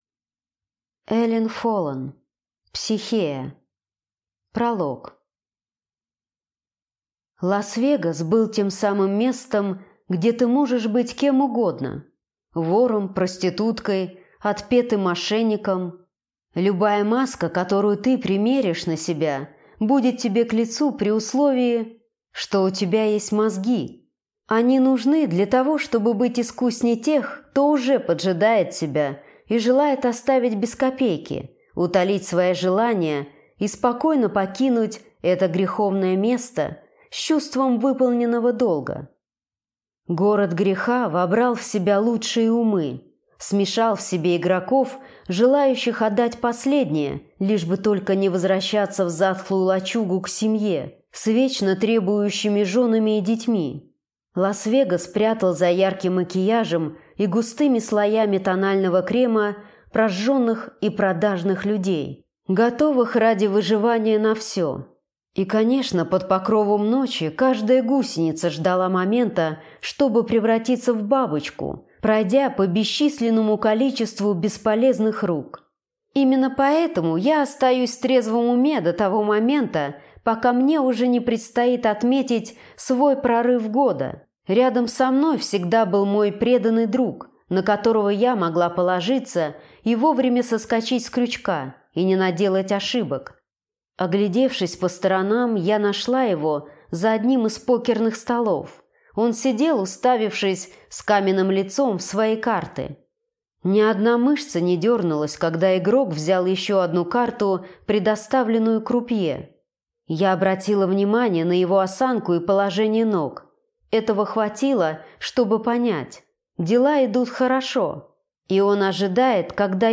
Аудиокнига Психея | Библиотека аудиокниг